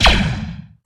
blaster_b2.mp3